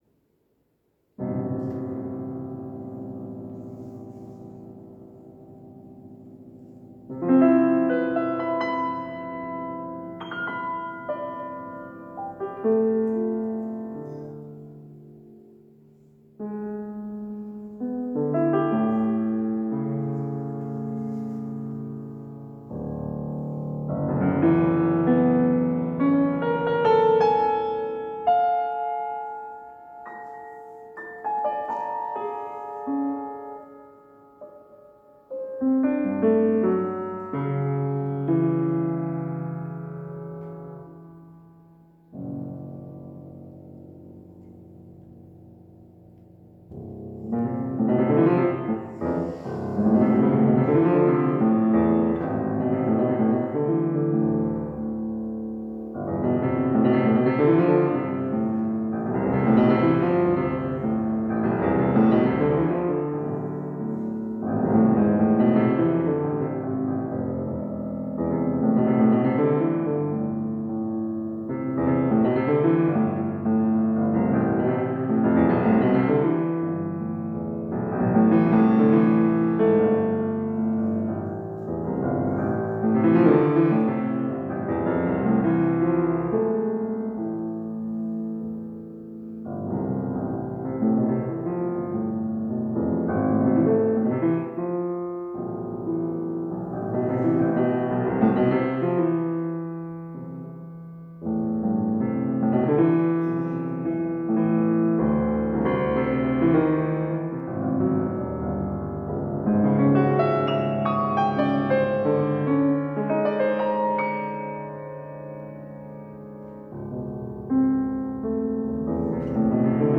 (Konzertmitschnitt 17.11.2023 Johanneskirche Zürich)